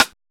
LINN RIM HI.wav